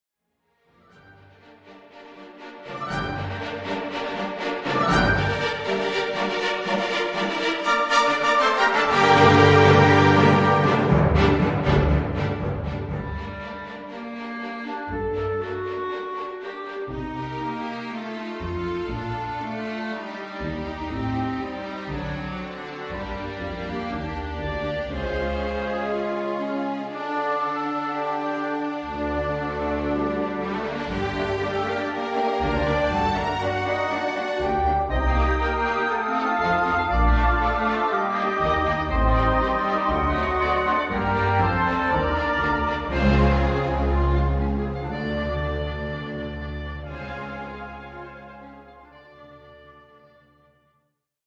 Clarinet in Bflat and Orchestra